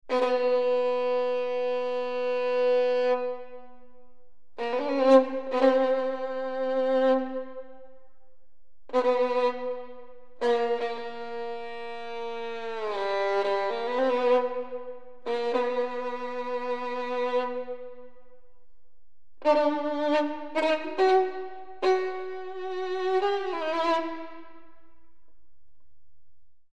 Super Audio CD